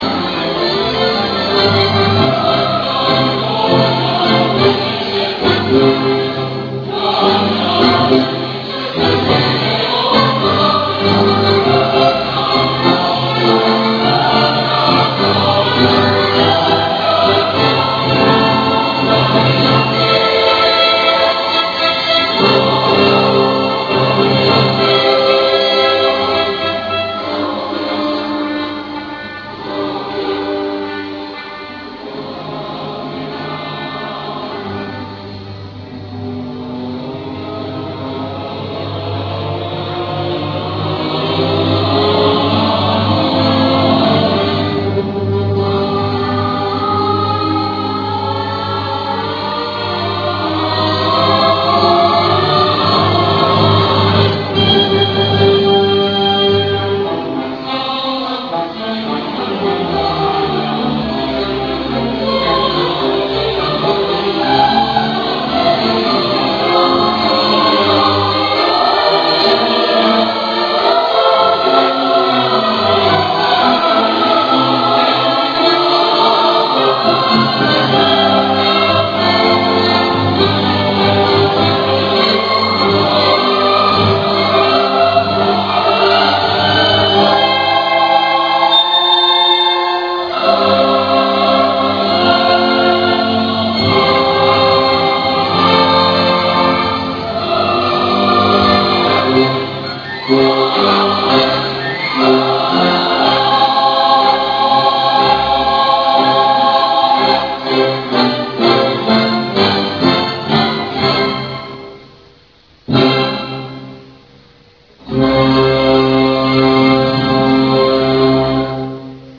Here is a part by the European Choir of Istanbul that you can hear with Real Audio (if you wait for 3-4 minutes) :